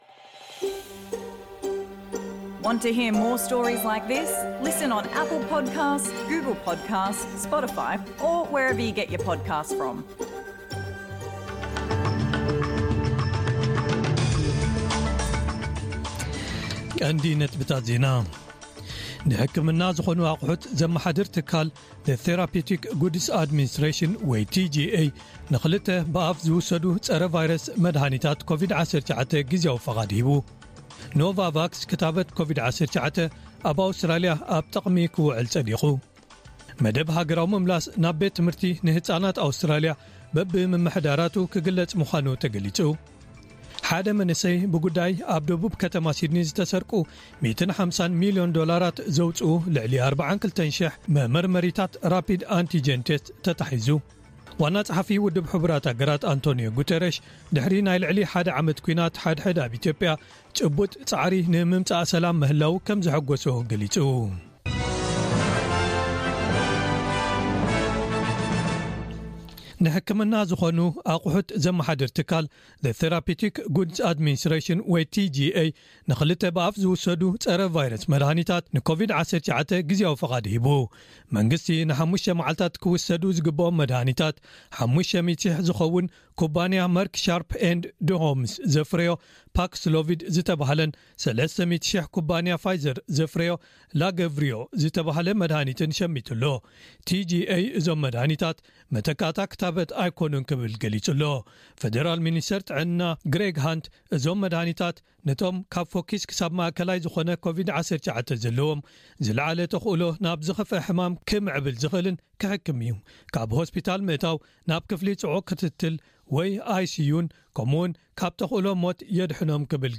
ዕለታዊ ዜና ኤስቢኤስ ትግርኛ፡